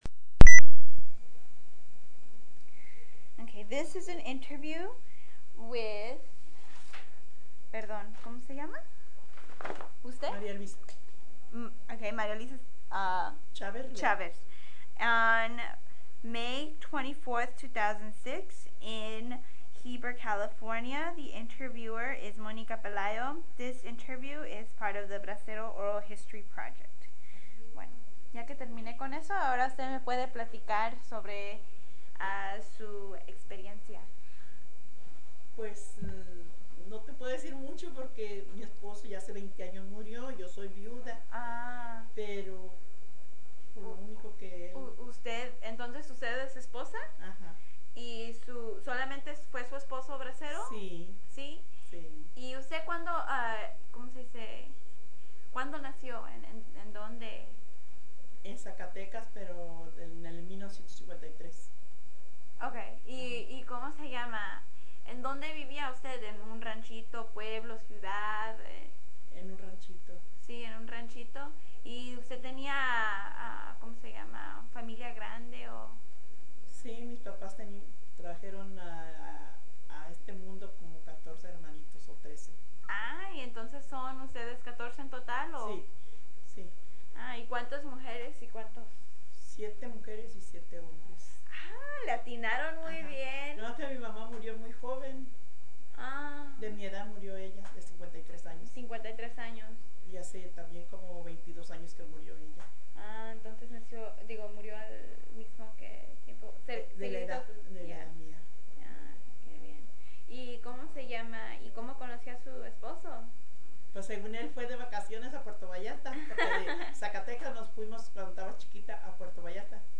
Interviewer
Heber, CA